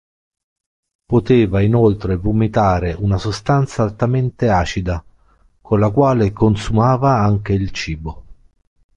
/sosˈtan.t͡sa/